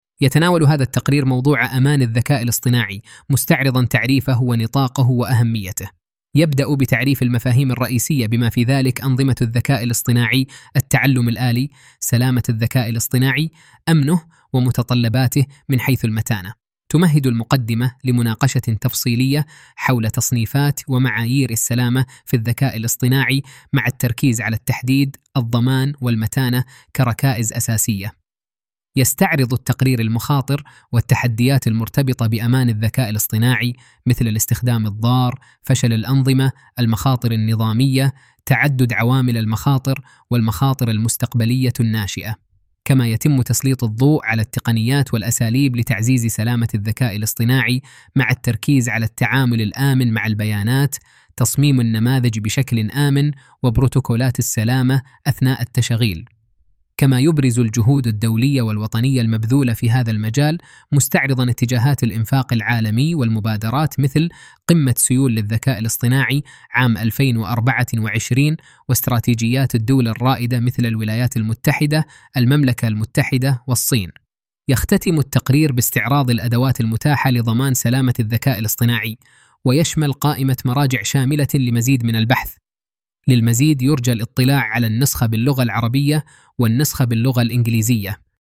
استكشف نماذج عملية اصدرت عن طريق منصتنا من فيديوات، مقاطع صوتية، مقالات، وغيرها ترجمت و دبلجت الى مختلف اللغات